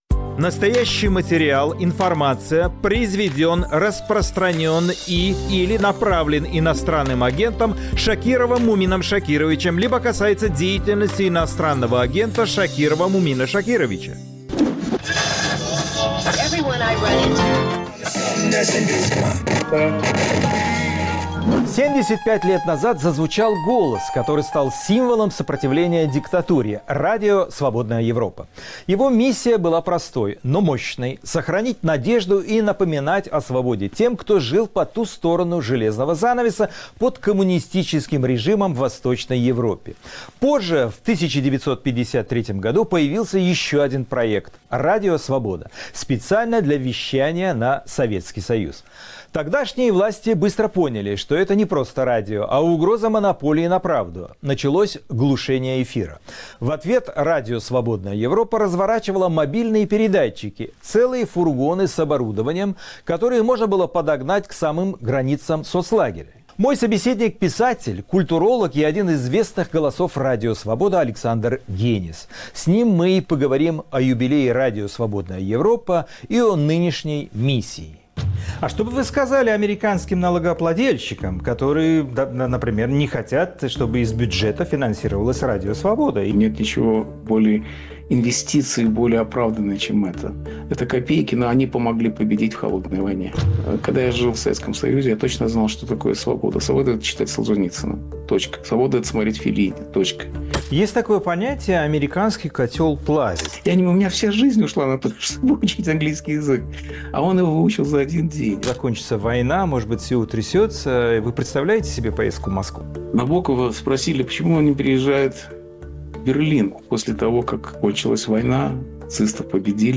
Александр Генис — писатель, культуролог — в разговоре о войне, эмиграции и разочарованиях.